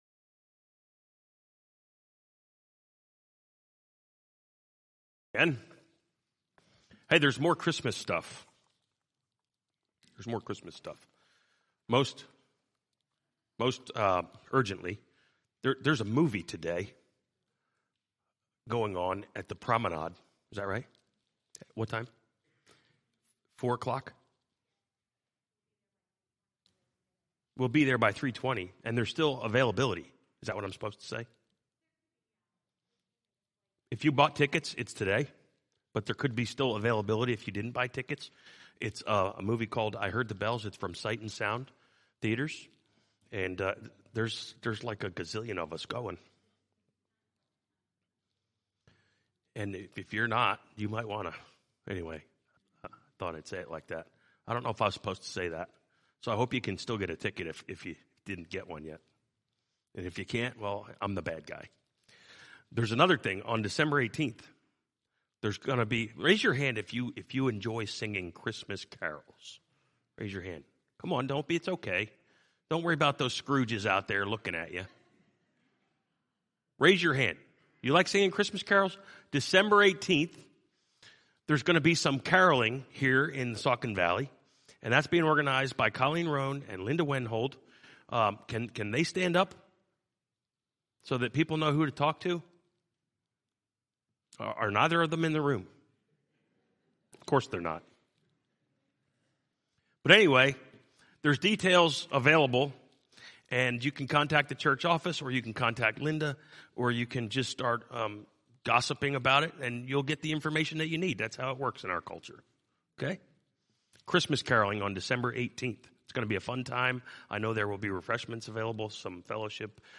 From Series: "2022 Sermons"